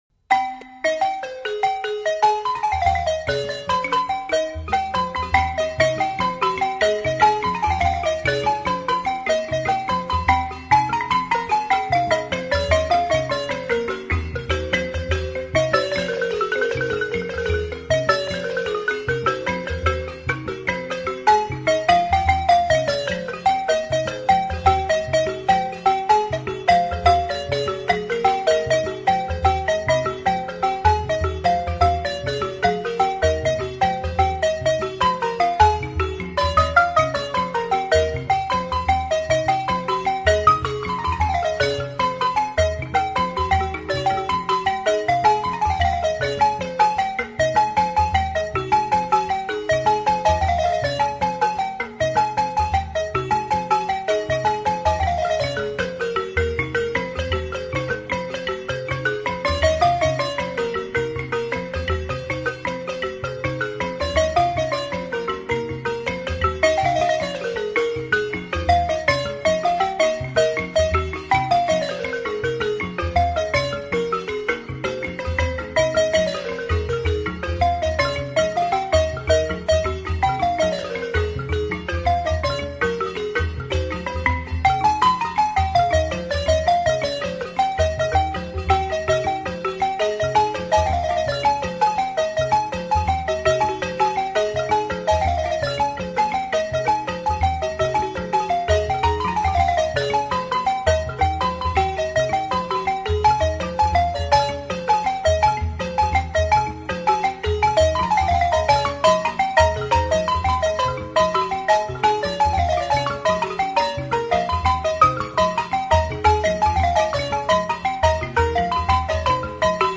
Guinea_Xylophon04.mp3